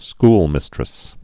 school·mis·tress
(sklmĭstrĭs)